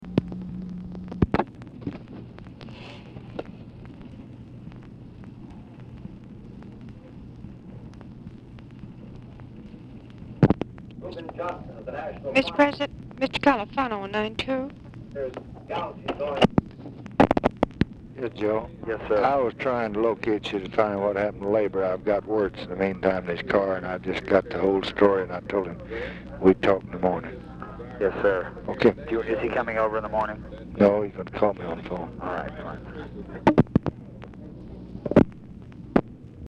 TV OR RADIO AUDIBLE IN BACKGROUND
Specific Item Type Telephone conversation